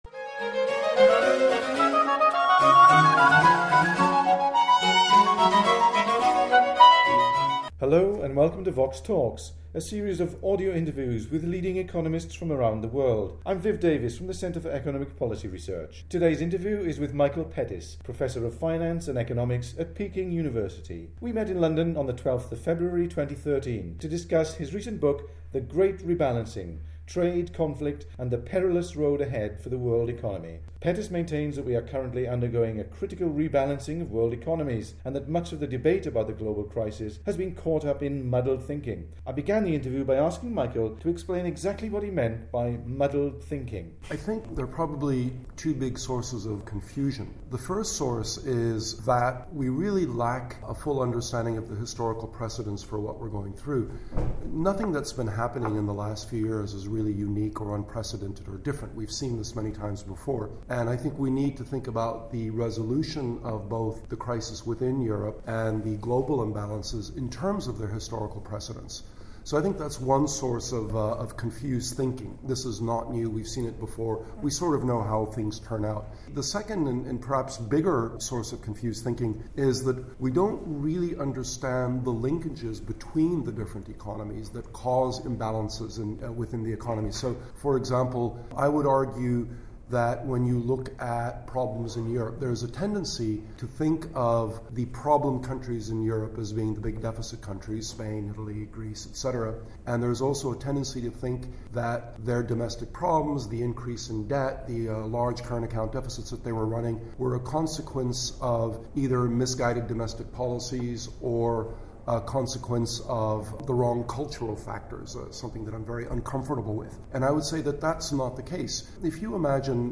Pettis explains how China has maintained massive, but unsustainable investment growth by artificially lowering the costs of capital, and how Germany may be endangering the euro by favouring its own development at the expense of its neighbours. Pettis maintains that we are currently undergoing a critical rebalancing of world economies, and that much of the debate about the global crisis has been caught up in ‘muddled thinking’. The interview was recorded in London on 12 February 2013.